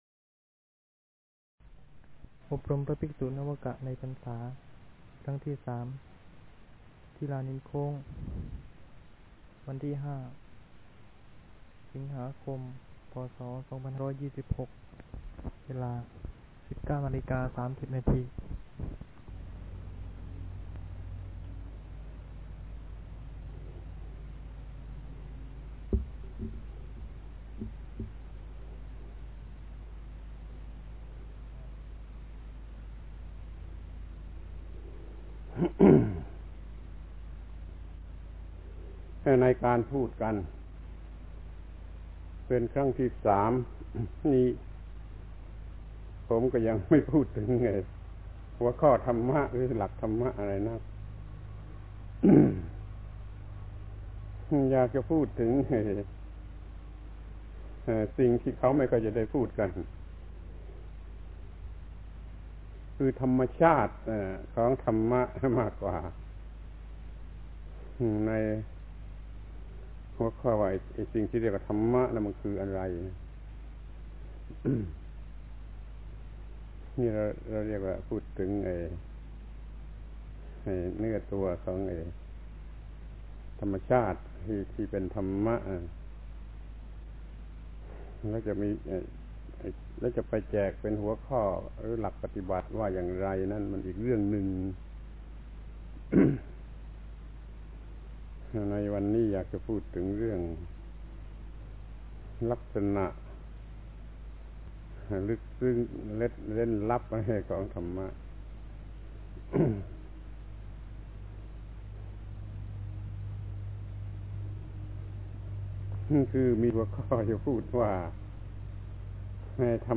พระธรรมโกศาจารย์ (พุทธทาสภิกขุ) - อบรมพระนวกะในพรรษา ปี 2526 ธรรมะเผด็จการ